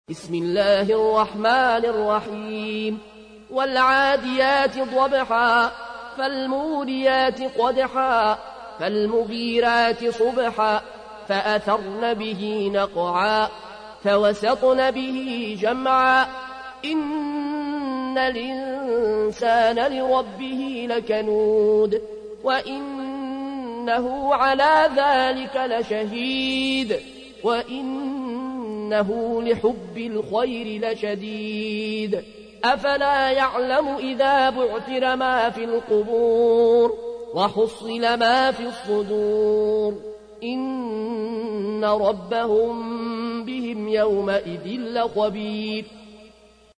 تحميل : 100. سورة العاديات / القارئ العيون الكوشي / القرآن الكريم / موقع يا حسين